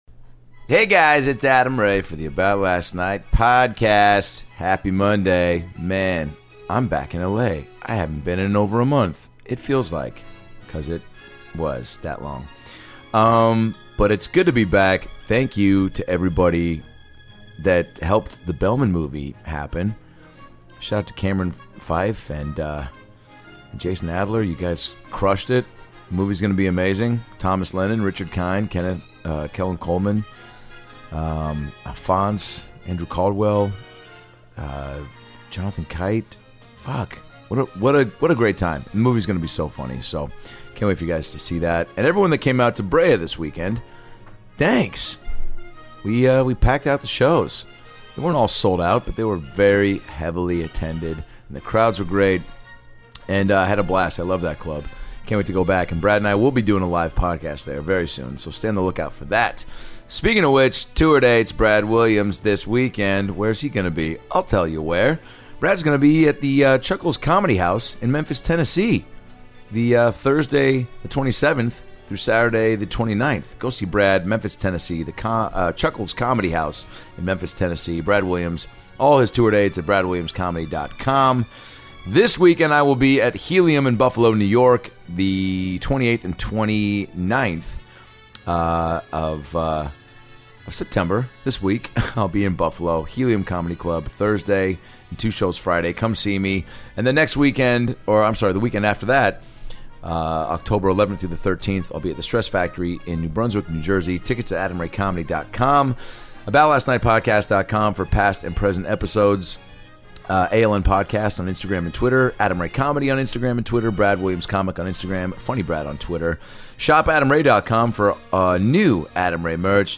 On this ALN CLASSIC episode, we're revisiting the AMAZING Tony Danza Interview from December 2014!